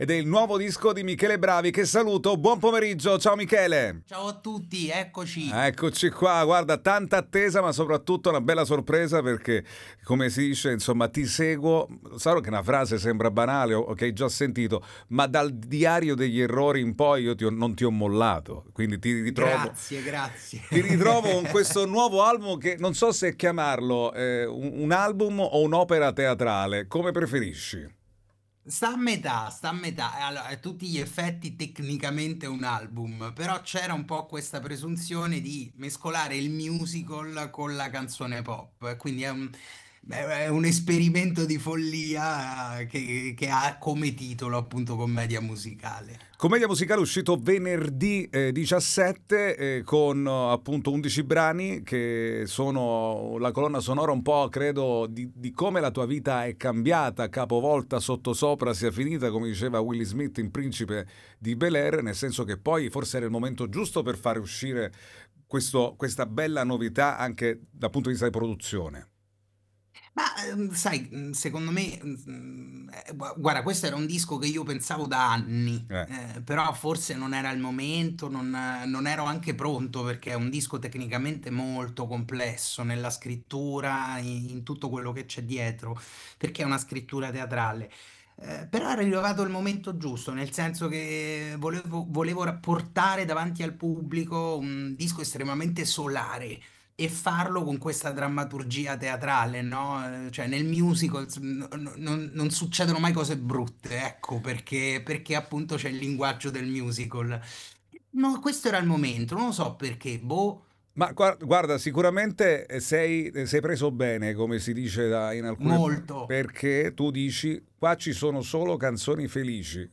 INTERVISTA MICHELE BRAVI